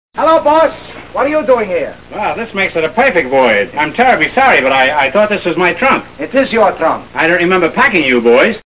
Category: Movies   Right: Personal
Tags: A Night at the Opera Marx Brothers The Marx Brothers A Night at the Opera clips A Night at the Opera sound clips